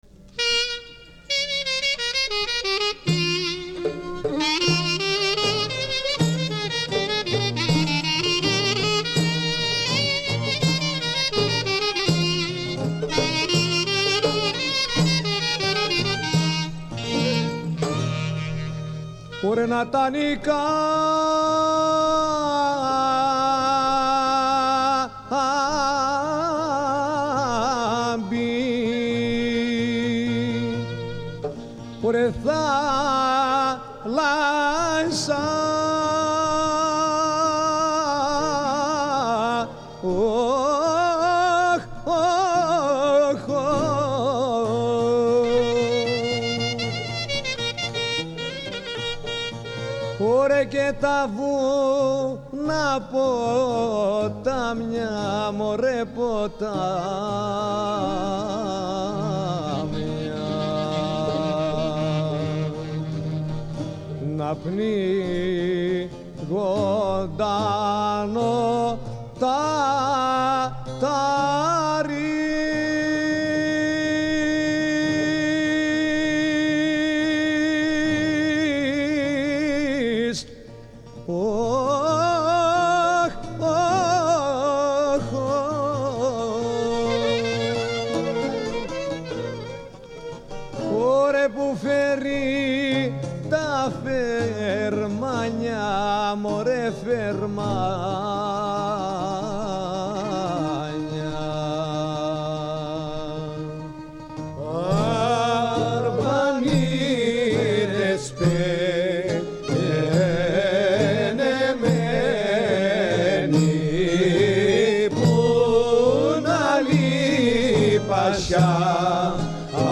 Παραδοσιακό, Τραγούδι
Ηπειρώτικη Μουσική Παράδοση